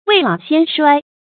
未老先衰 注音： ㄨㄟˋ ㄌㄠˇ ㄒㄧㄢ ㄕㄨㄞ 讀音讀法： 意思解釋： 年紀還不大就衰老了。